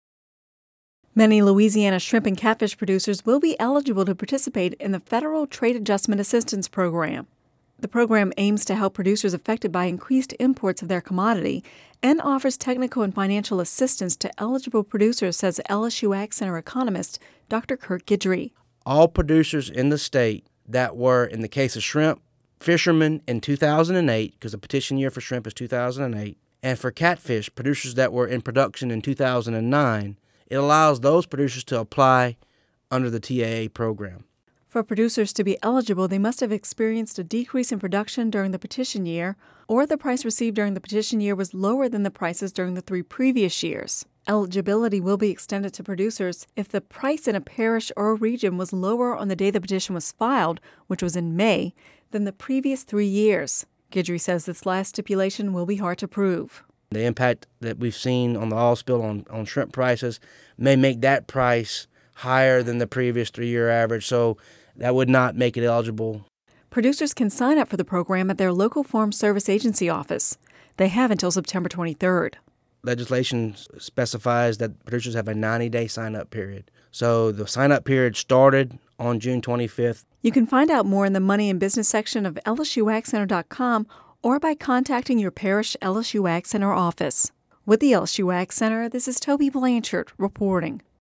(Radio News 7/26/10) Many Louisiana shrimp and catfish producers will be eligible to participate in the federal Trade Adjustment Assistance program.